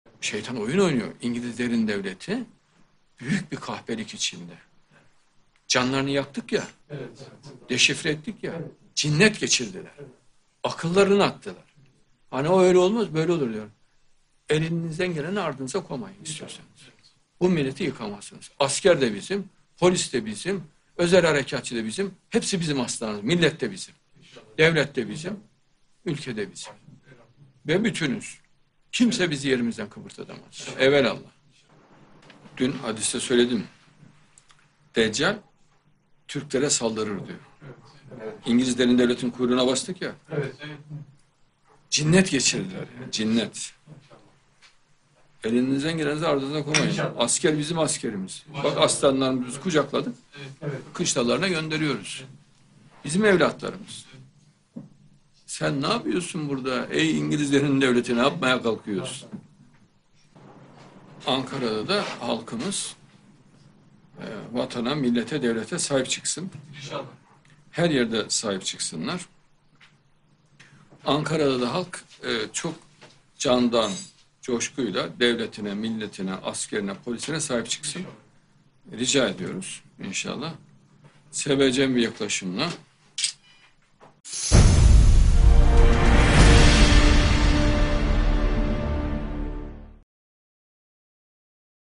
Extrait de conversation en direct de M. Adnan Oktar du 15 juillet 2016 Adnan Oktar: Satan machine un complot; l'état profond britannique commet un...